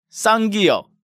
Category:Hangeul sounds